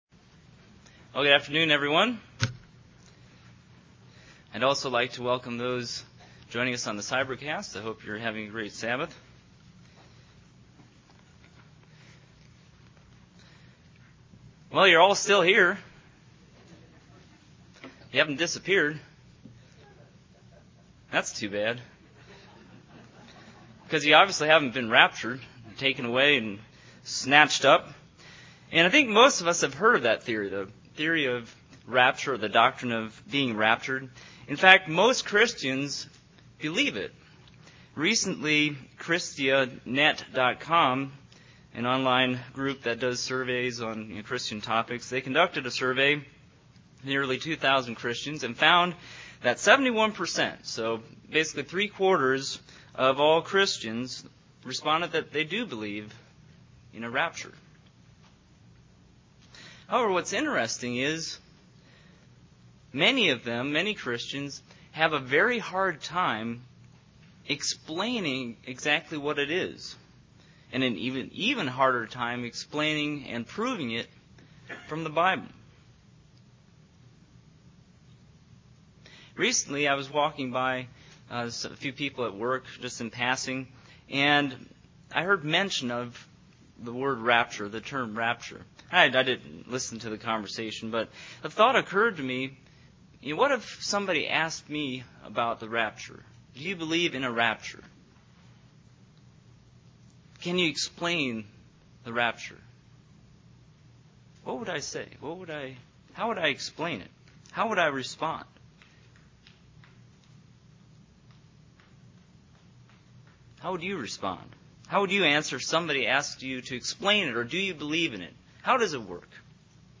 Given in North Canton, OH
UCG Sermon Studying the bible?